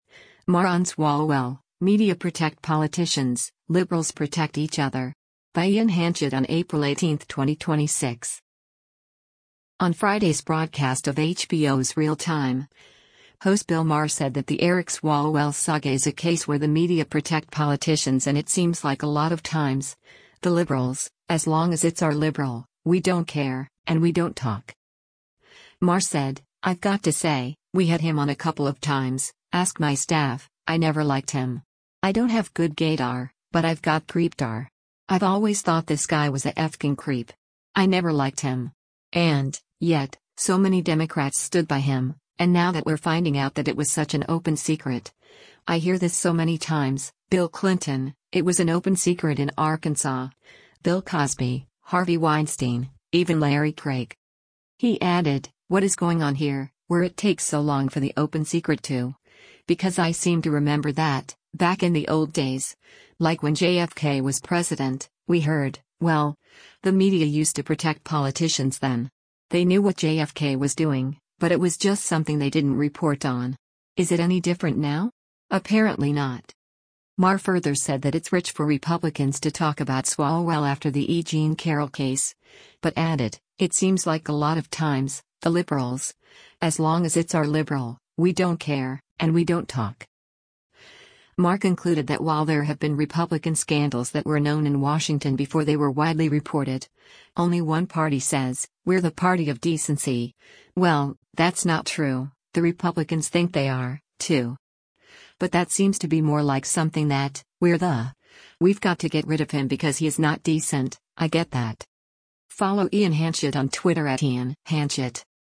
On Friday’s broadcast of HBO’s “Real Time,” host Bill Maher said that the Eric Swalwell saga is a case where the media “protect politicians” and “It seems like a lot of times, the liberals, as long as it’s our liberal, we don’t care, and we don’t talk.”